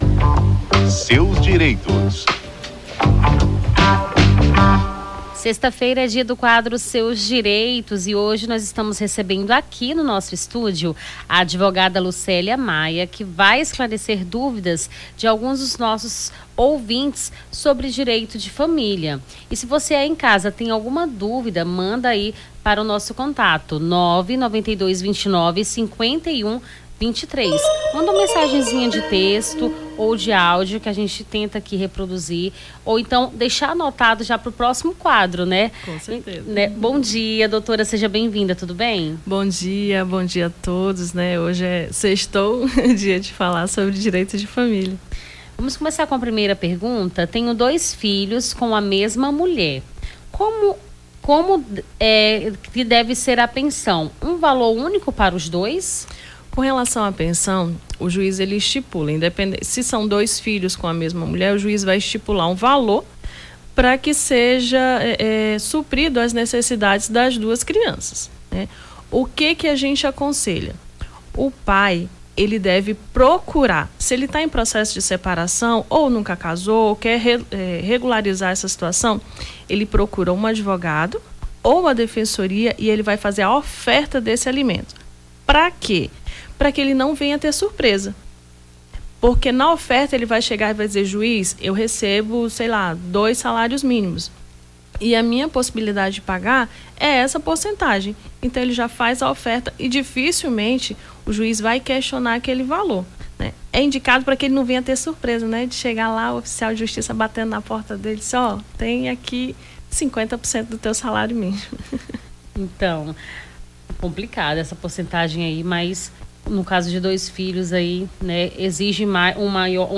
AO VIVO: Confira a Programação
Seus Direitos: advogada tira-dúvidas dos ouvintes sobre o direito de família